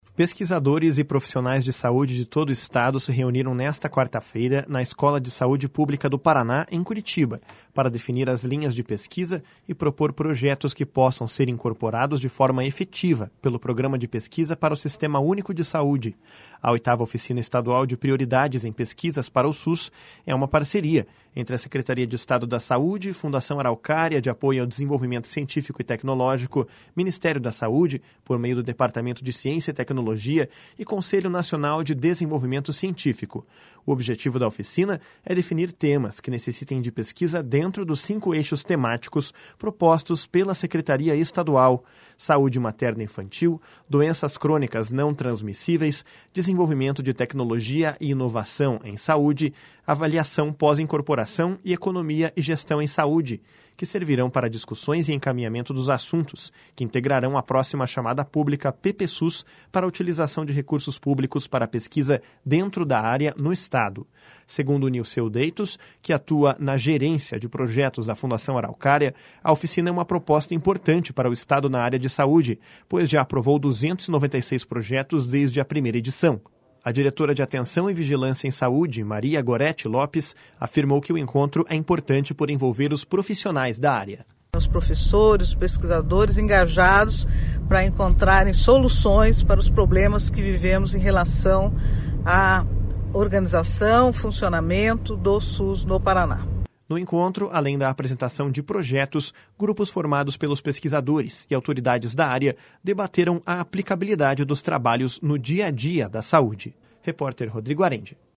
No encontro, além da apresentação de projetos, grupos formados pelos pesquisadores e autoridades da área debateram a aplicabilidade dos trabalhos no dia a dia da saúde. (Repórter: